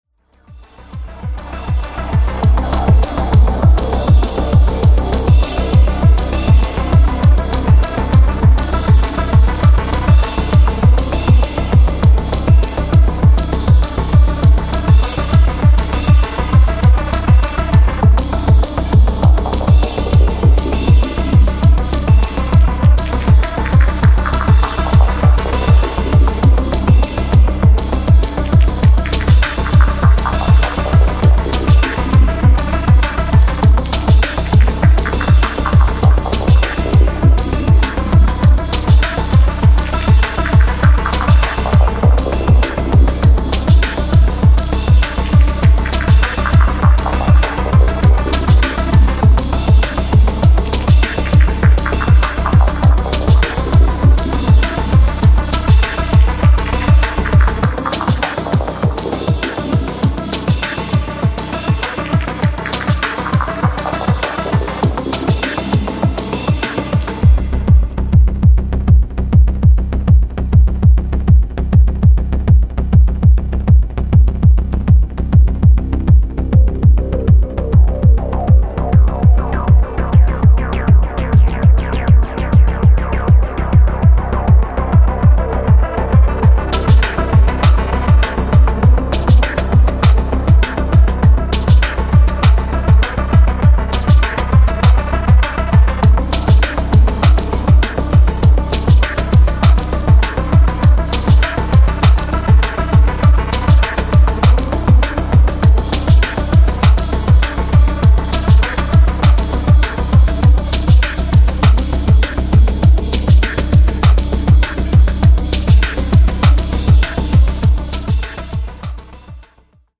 これが凄い迫力！